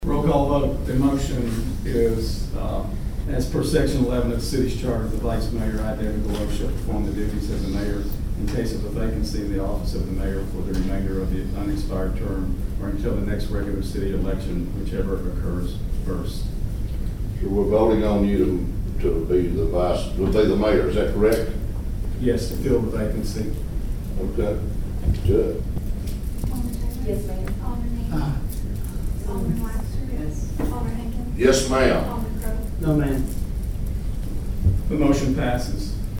After Mr. Crowe expressed his concerns, the Board took a vote and commentary can be heard from those in attendance.